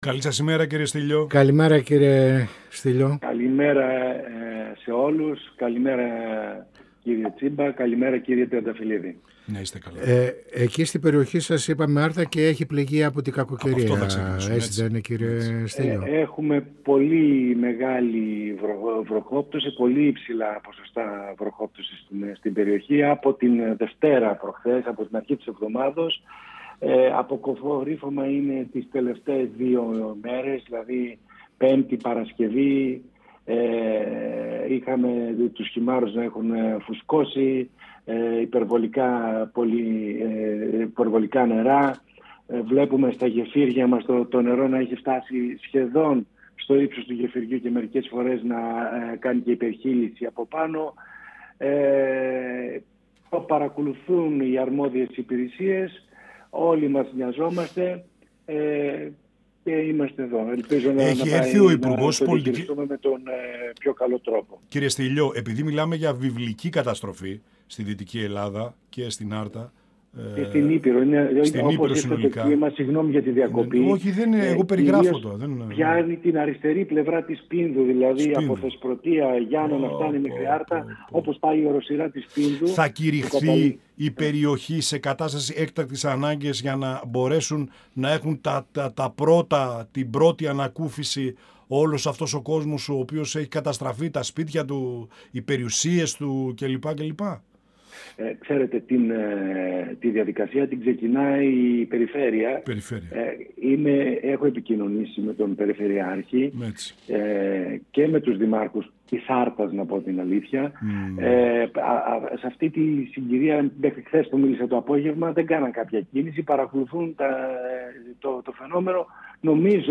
Στις μεγάλες καταστροφές, λόγω των ακραίων καιρικών συνθηκών στην ευρύτερη περιοχή της Δυτικής Ελλάδας, όπως και στο σκάνδαλο του ΟΠΕΚΕΠΕ αναφέρθηκε ο Βουλευτής της Ν.Δ. Γιώργος Στύλιος, μιλώντας στην εκπομπή «Πανόραμα Επικαιρότητας» του 102FM της ΕΡΤ3.
Συνεντεύξεις